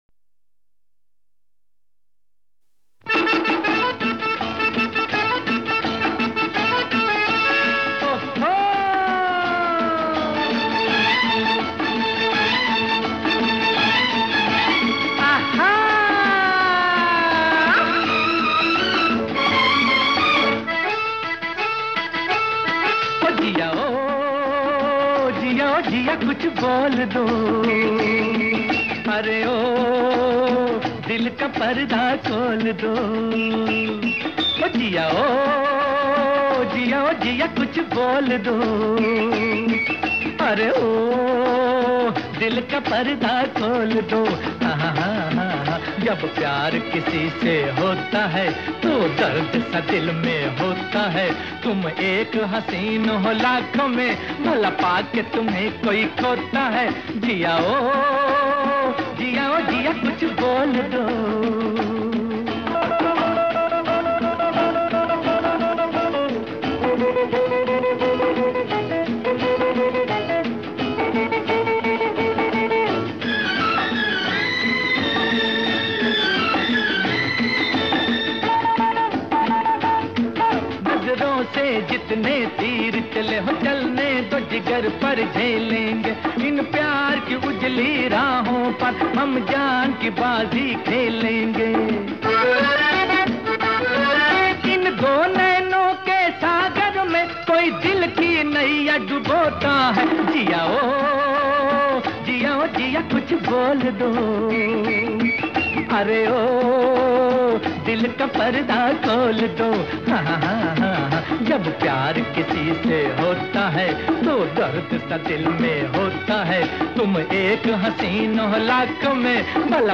(Male Vocals)